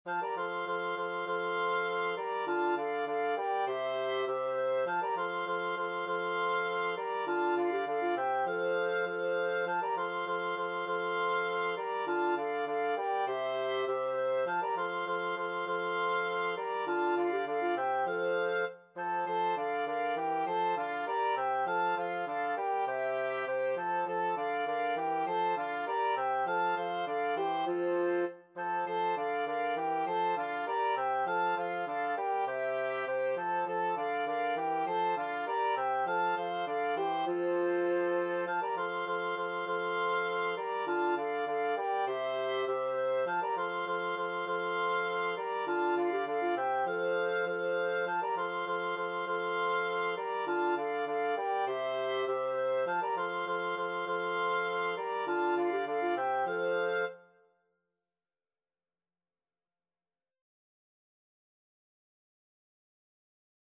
Free Sheet music for Wind Quartet
FluteOboeClarinetBassoon
F major (Sounding Pitch) (View more F major Music for Wind Quartet )
4/4 (View more 4/4 Music)
Wind Quartet  (View more Easy Wind Quartet Music)
Classical (View more Classical Wind Quartet Music)